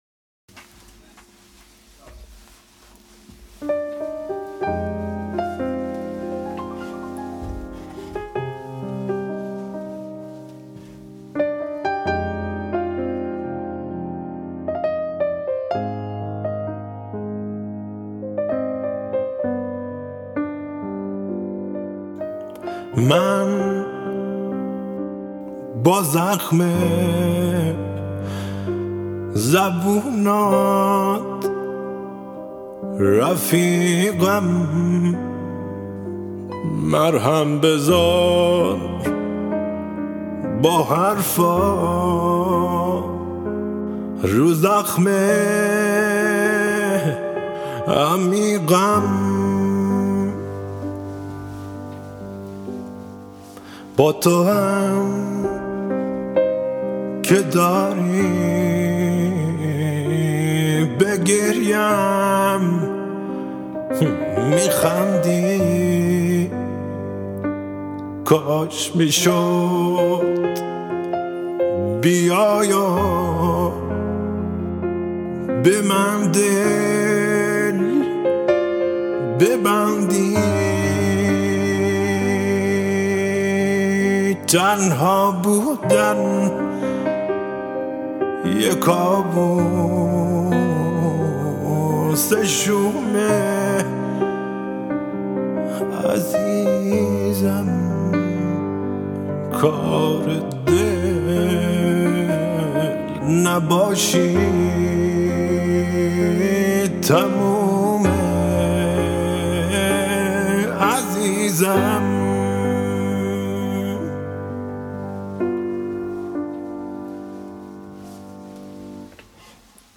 این قطعه‌ی احساسی و عمیق